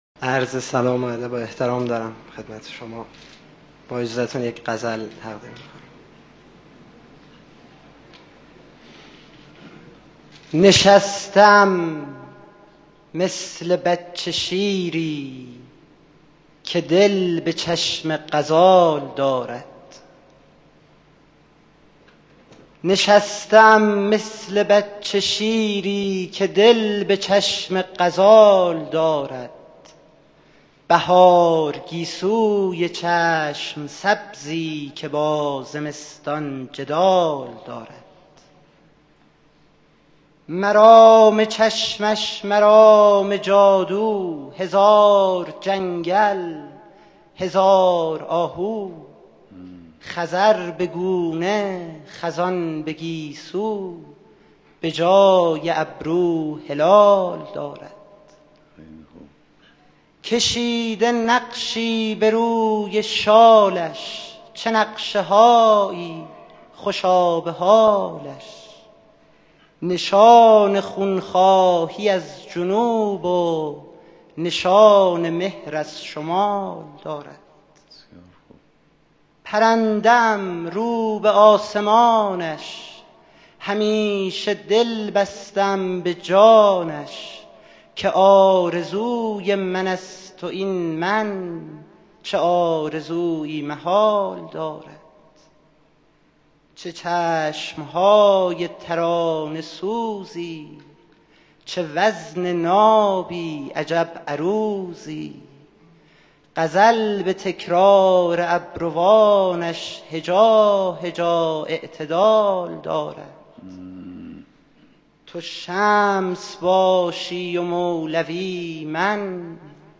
شعرخوانی
در حضور رهبری